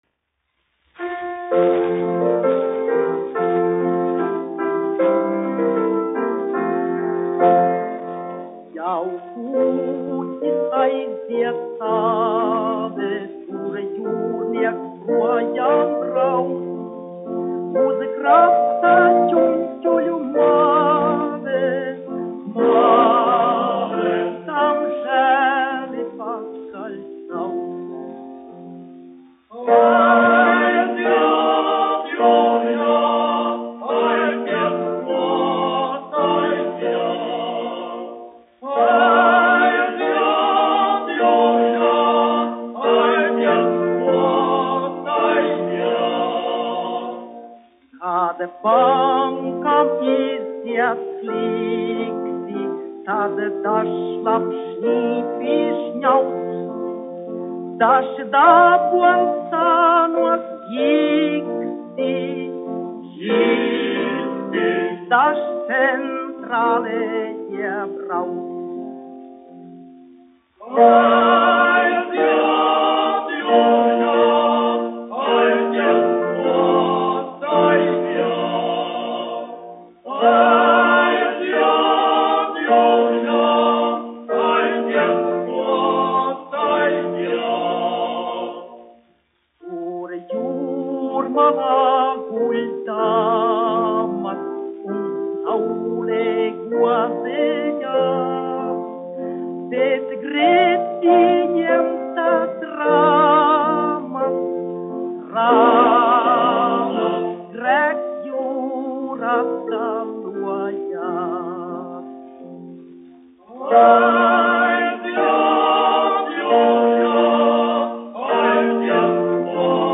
1 skpl. : analogs, 78 apgr/min, mono ; 25 cm
Vokālie seksteti
Skaņuplate